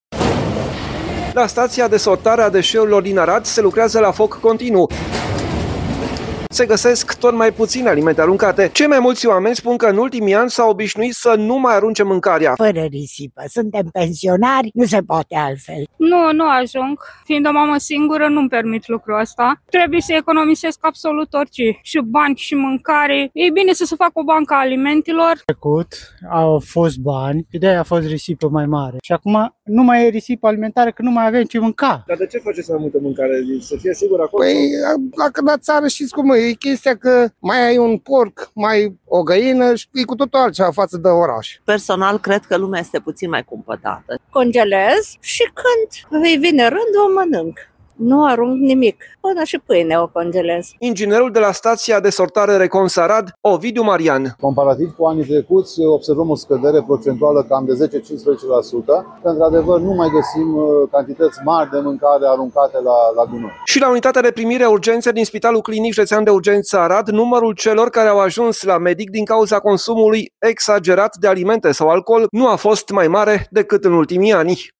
La stația de sortare a deșeurilor din Arad se lucrează la foc continuu.
„Fără risipă. Suntem pensionari, nu se poate altfel”, a spus o femeie.
„În trecut au fost bani și de aia a fost risipă mai mare. Acum nu mai e risipă alimentară că nu mai avem ce mânca”, susține un bărbat.